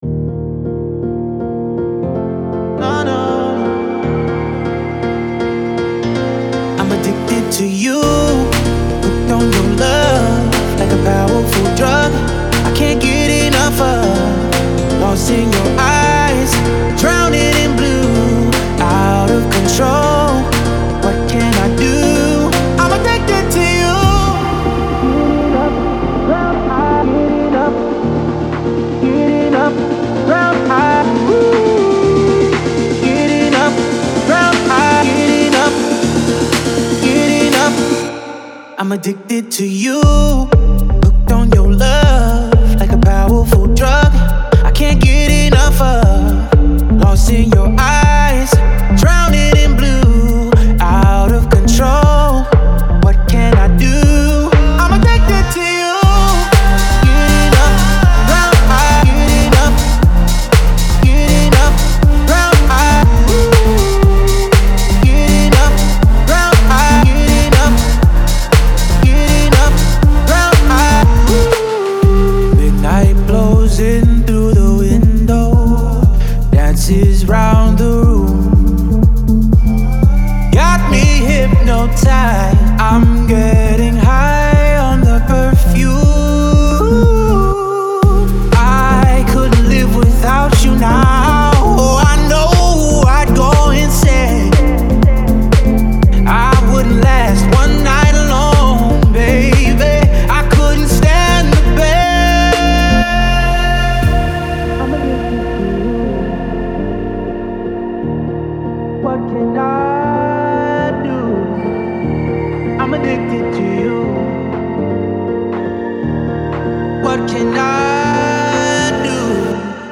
это энергичная электронная композиция в жанре EDM